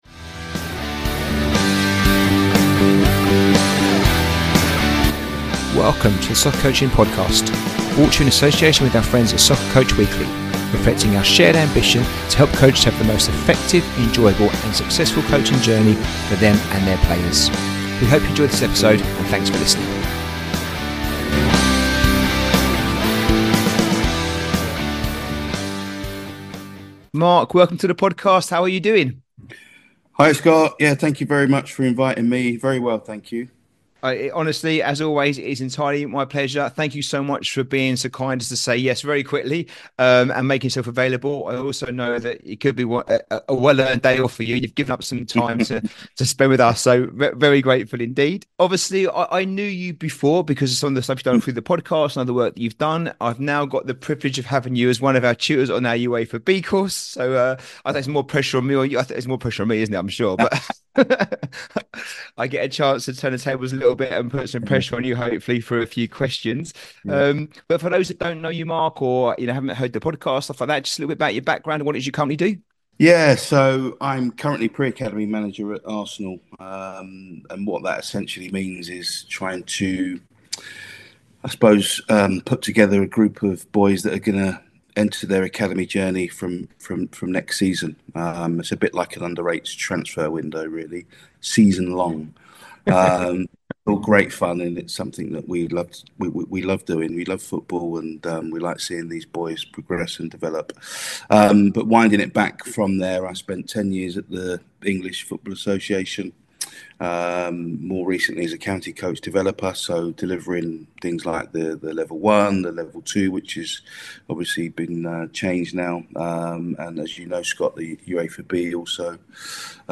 Episode 108 - How To Deliver An Effective Training Session, a conversation